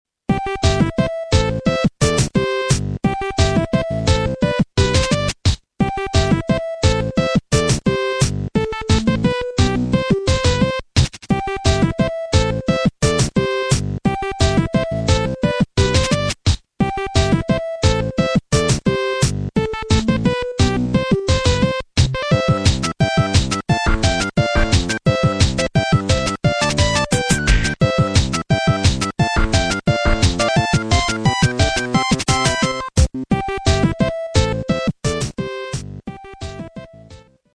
（音質　16kbps〜48kbps　モノラル）